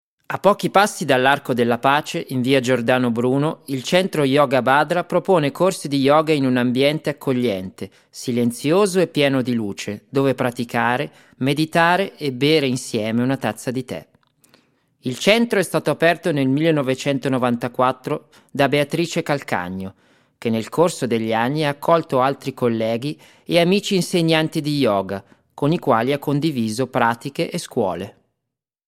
Singer, Actor, Musician & native italian, german and english native speaker active as a live music performer, professional Tv speaker and Tour Guide since 2011.
Sprechprobe: eLearning (Muttersprache):
Italian Informative.mp3